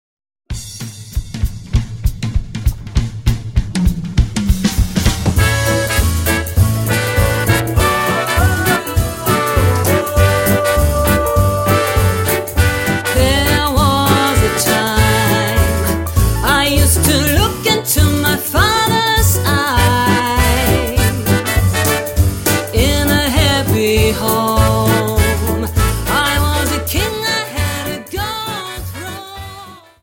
Dance: Quickstep